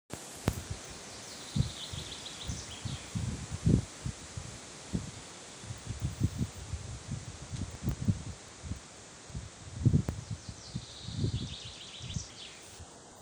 зяблик, Fringilla coelebs
Ziņotāja saglabāts vietas nosaukumsĀRLAVA
СтатусПоёт